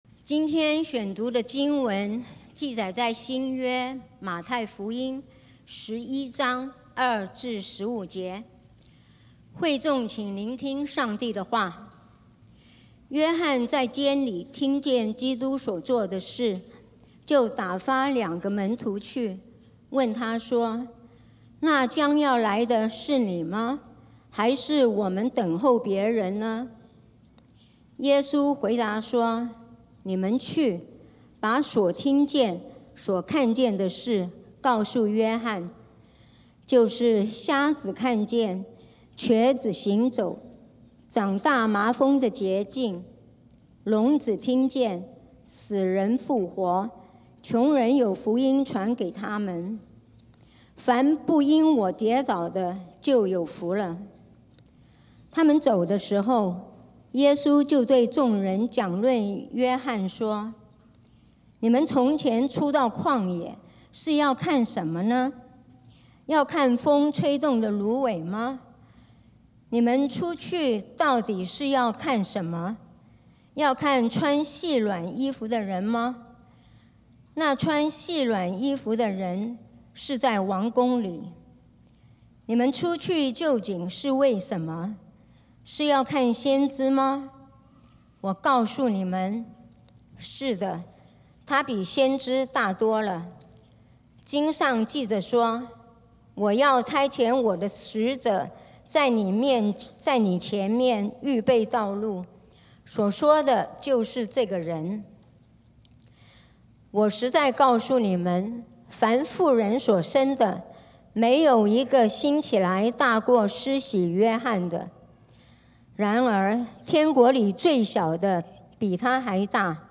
12/14/2025 講道經文：馬太福音 Matthew 11:2-15 本週箴言：羅馬書 Romans14:7-8 「我們沒有一個人為自己活，也沒有一個人為自己死。